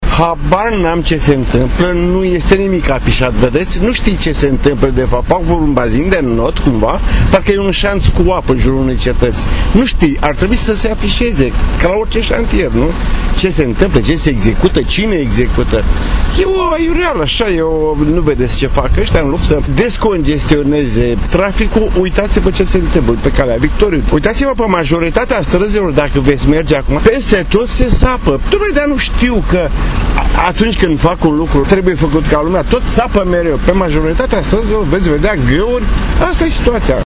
sofer-la-Charles-de-Gaulle.mp3